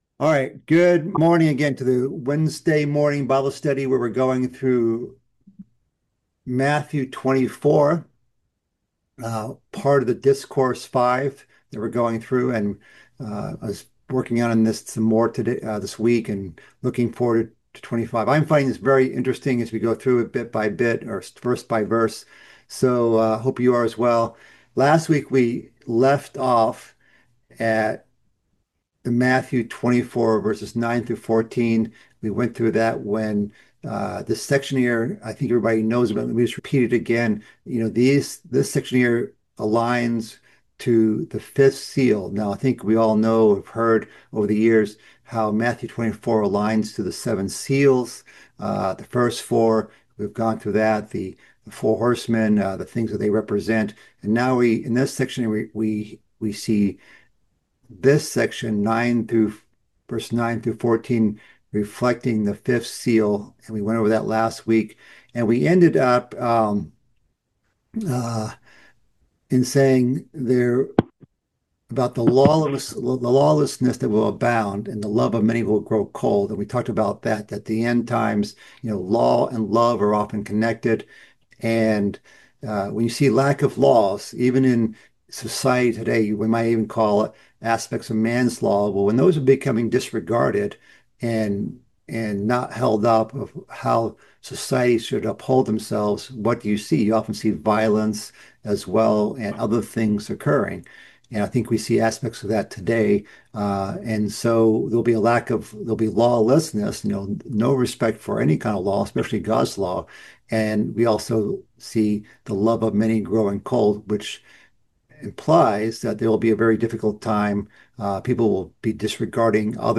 This is the eighth part of a mid-week Bible study series covering Christ's fifth discourse in the book of Matthew.